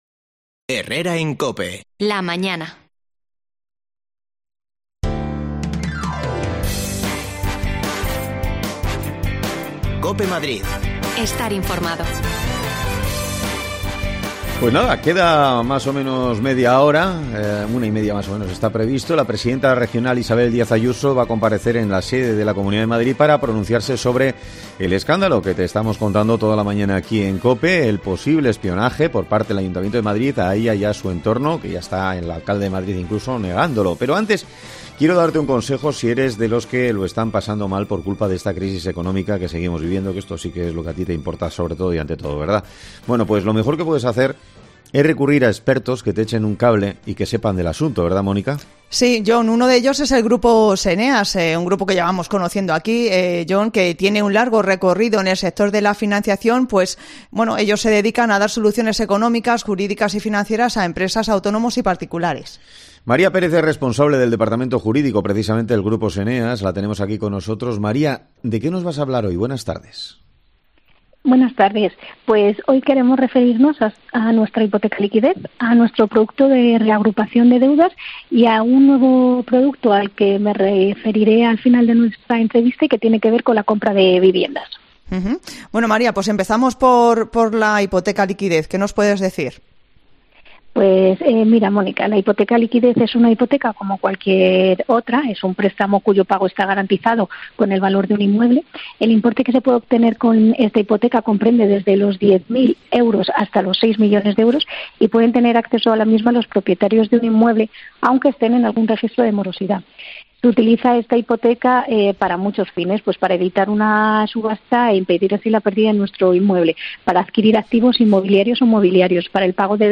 AUDIO: El proyecto estrella de soterrar la A5 todavia no tiene fecha de inicio. Hablamos con los vecinos de la zona